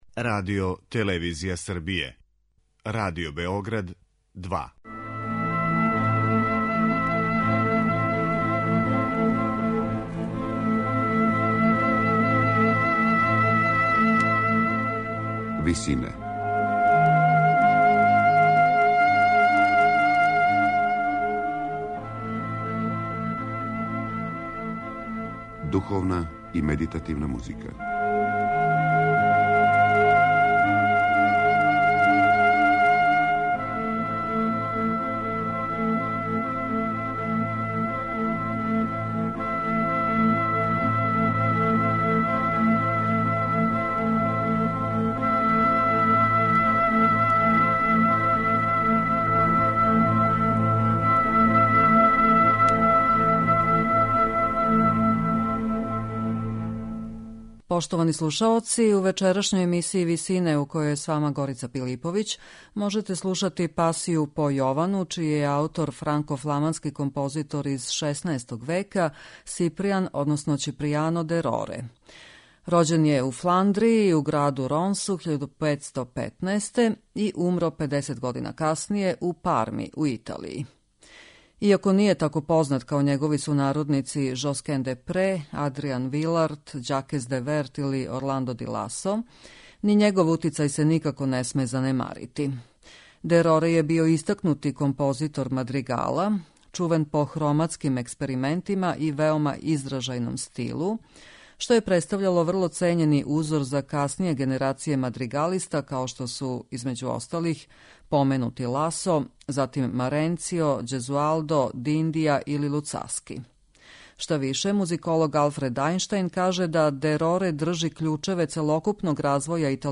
Емисија духовне и медитативне музике